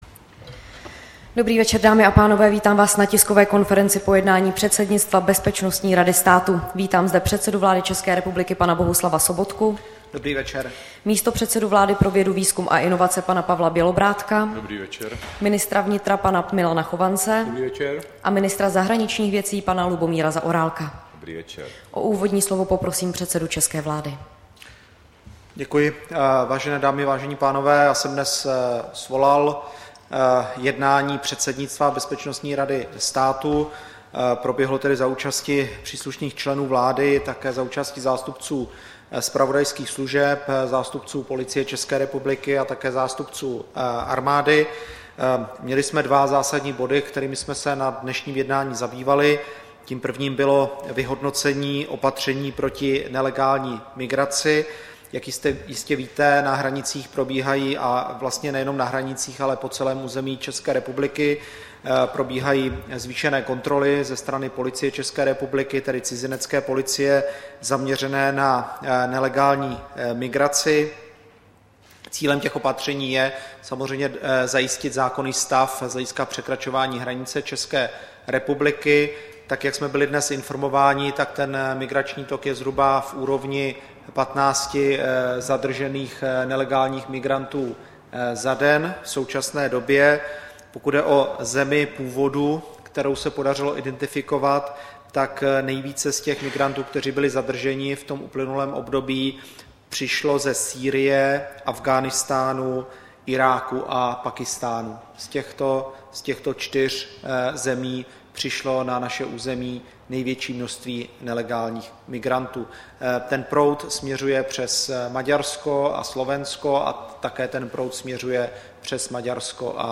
Tisková konference po zasedání předsednictva Bezpečnostní rady státu, 28. června 2015